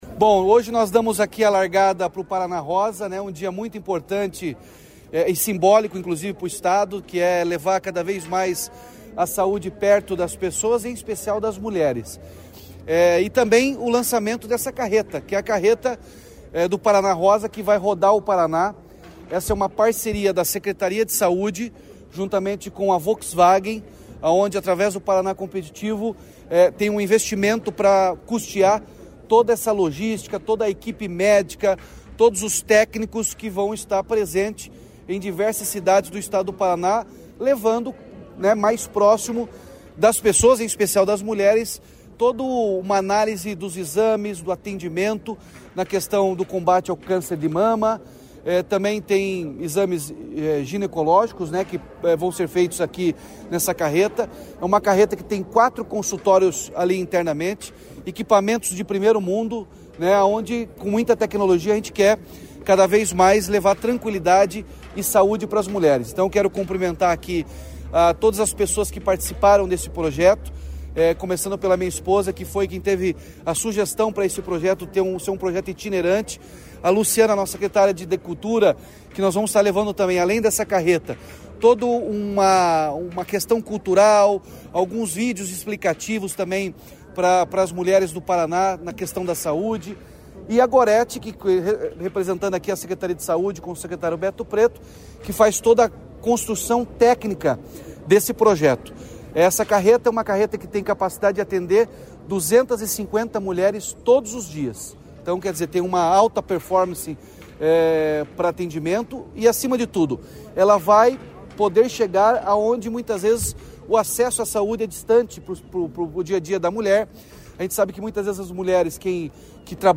Sonora do governador Ratinho Junior sobre o lançamento da Carreta Saúde da Mulher